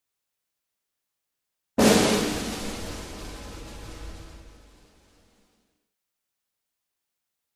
Snare Flam Orchestra Attention - Increasing